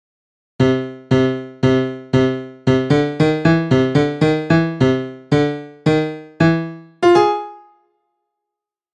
下手くそな演奏で、ピアノ曲です。
データ上で音を下げる方法が分からないので大きめです。。。汗 デエパリのテーマ